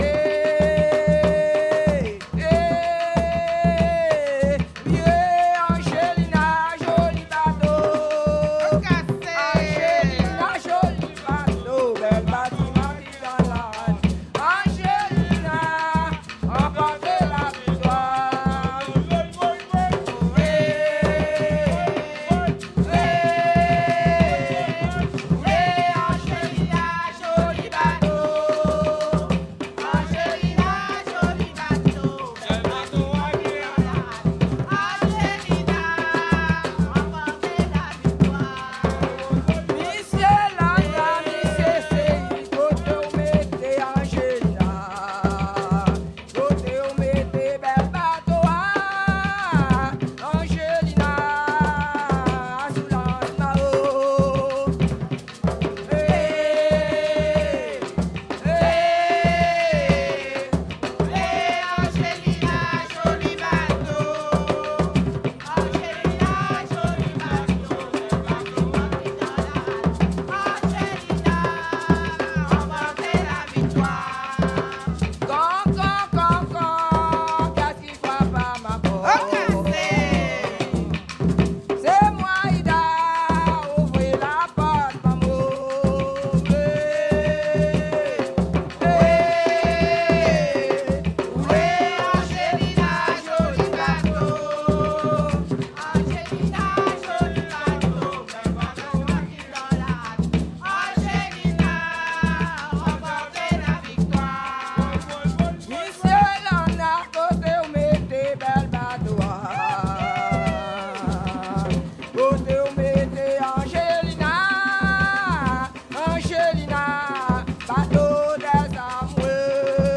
danse : kasékò (créole)
Pièce musicale inédite